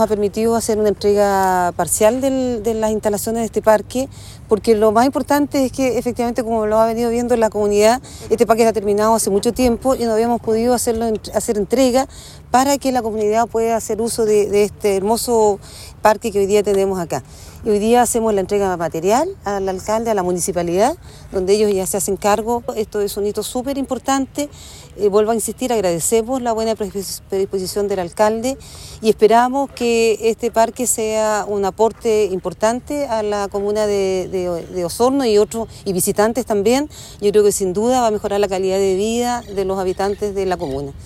La directora regional del Serviu, Isabel de la Vega, informó que esta es una entrega parcial que permitirá la apertura del parque a la comunidad en el corto plazo.